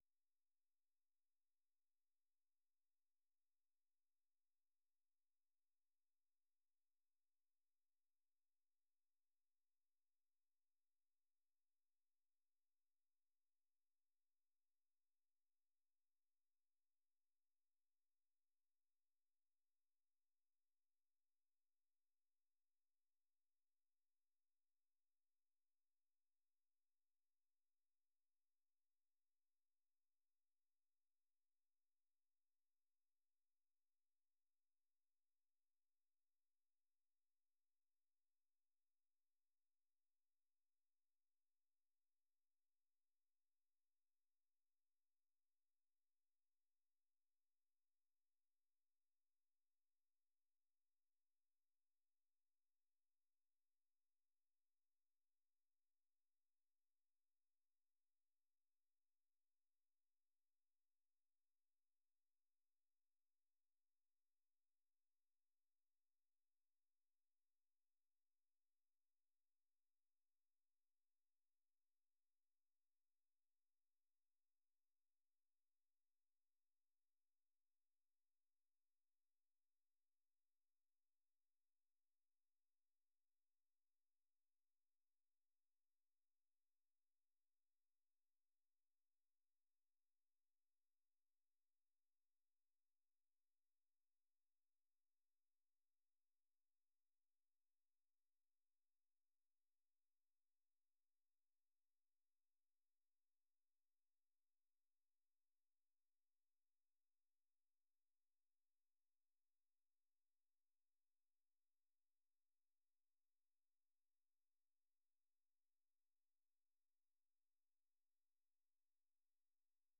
VOA 한국어 방송의 간판 뉴스 프로그램입니다. 한반도와 함께 미국을 비롯한 세계 곳곳의 소식을 빠르고 정확하게 전해드립니다. 다양한 인터뷰와 현지보도, 심층취재로 풍부한 정보를 담았습니다.